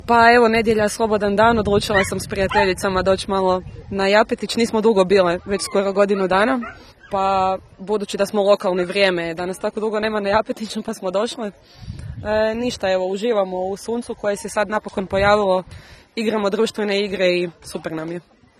Bili smo na ‘Japu’ i snimili dojmove posjetitelja